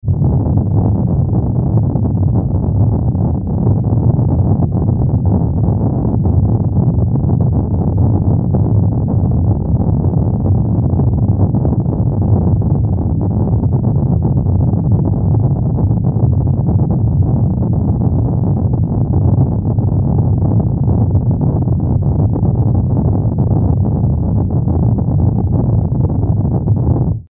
地響き 03(シンセ)
/ F｜演出・アニメ・心理 / F-50 ｜other アンビエント
ゴゴゴゴゴ